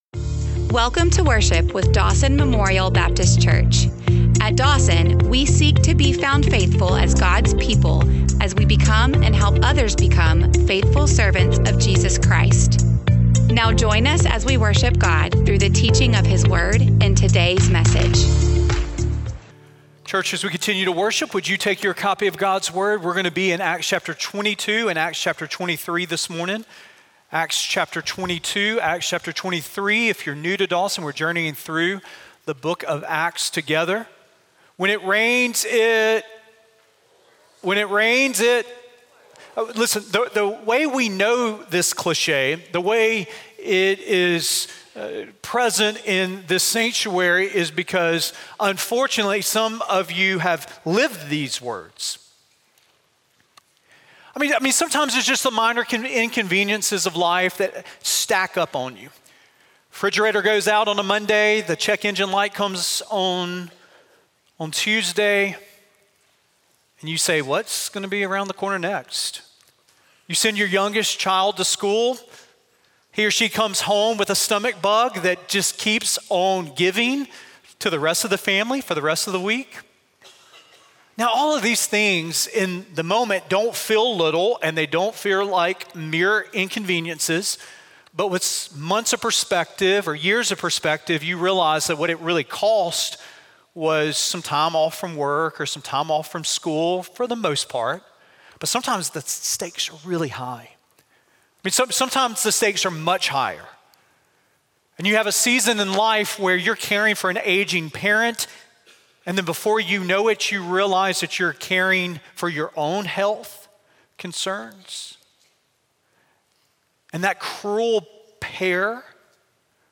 Sermon113audio.mp3